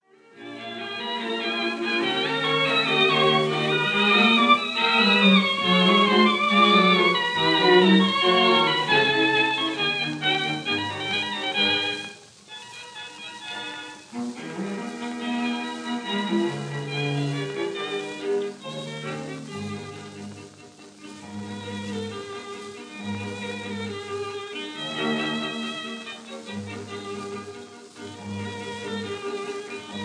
1st violin
2nd violin
viola
cello
2nd viola